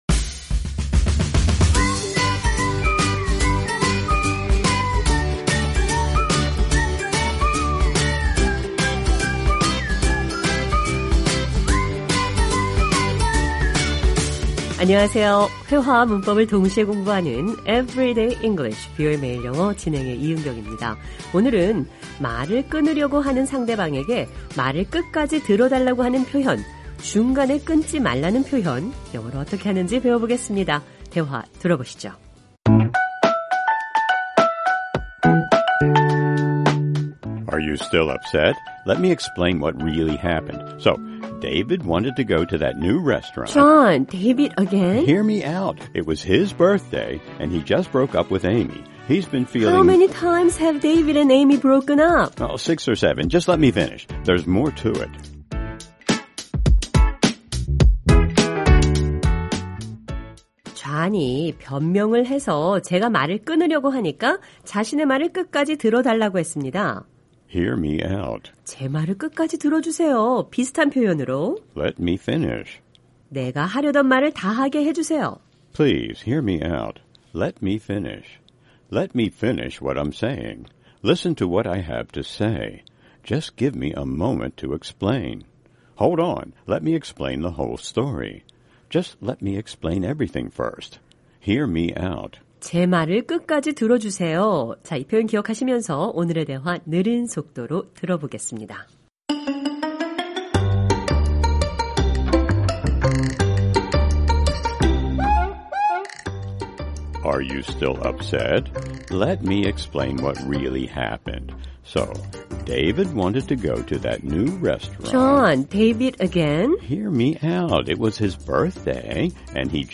오늘은 말을 끊으려고 하는 상대방에게 말을 끝까지 들어달라고 하는 표현, 중간에 끊지 말라는 표현 영어로 어떻게 하는지 배워보겠습니다. 대화 들어보시죠.